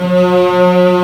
Index of /90_sSampleCDs/Roland L-CD702/VOL-1/CMB_Combos 1/CMB_mf Strings